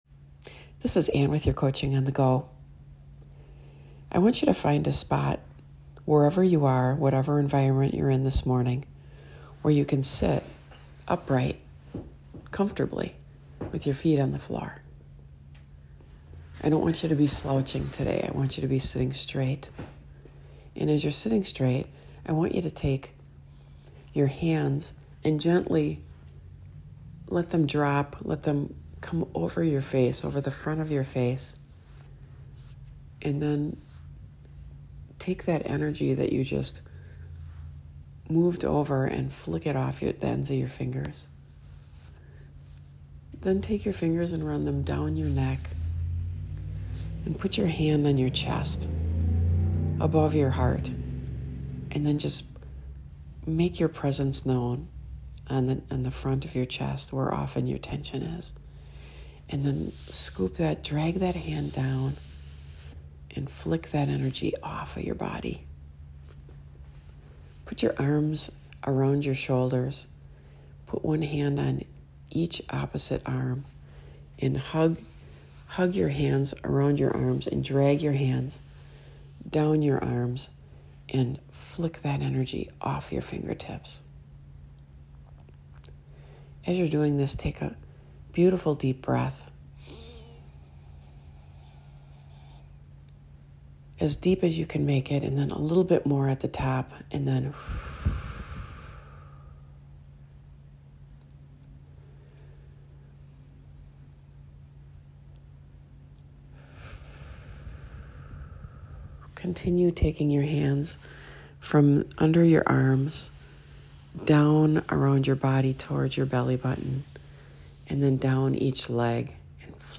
Today, I created a meditation for you.
SpiritRiver-434-Want-To-Meditate-Together-Today.m4a